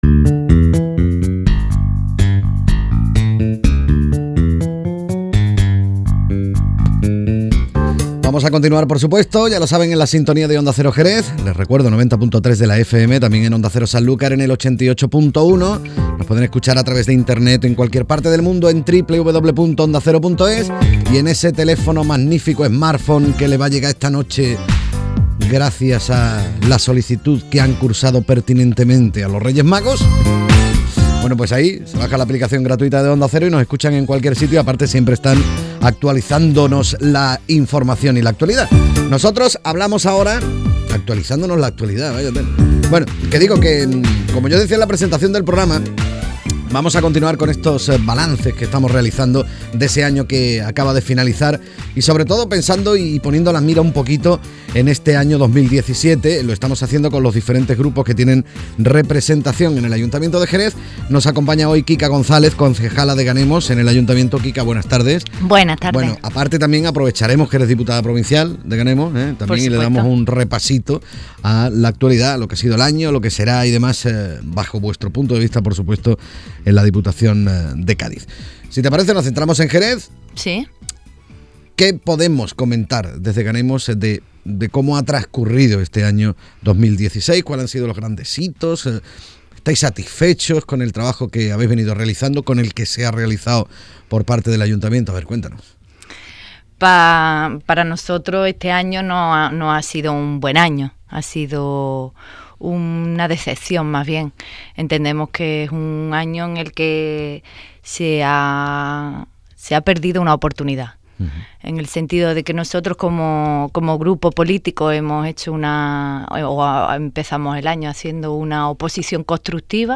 Esta tarde, nuestra diputada y concejala Kika González ha pasado por los micrófonos de Onda Cero Radio
Entrevista_a_Kika_González.mp3